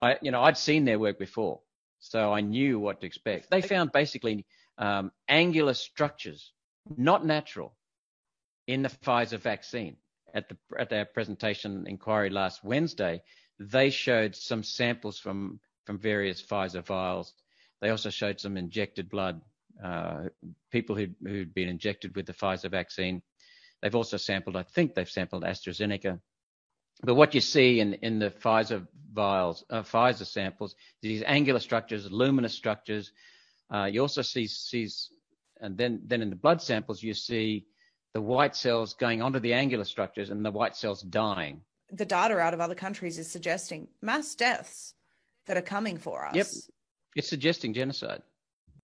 סנאטור אוסטרלי אומר שהעדויות מצביעות על רצח עם